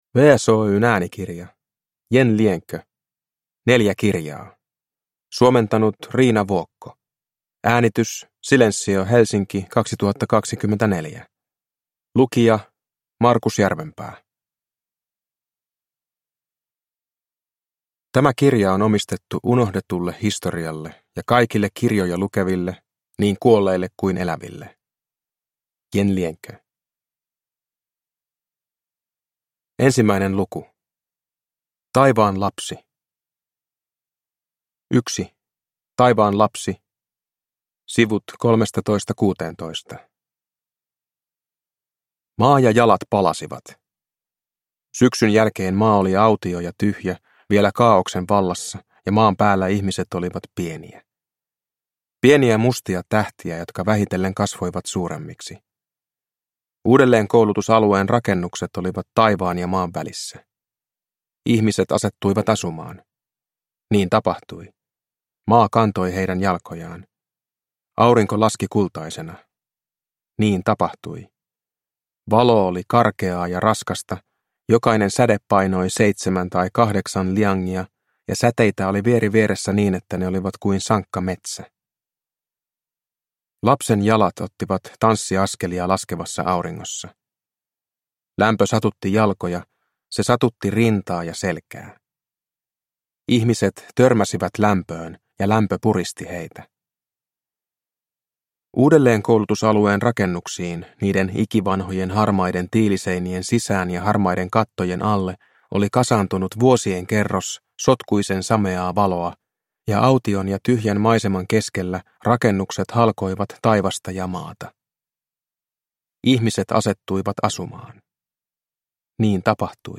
Neljä kirjaa (ljudbok) av Yan Lianke